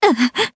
Ripine-Vox_Damage_jp_02.wav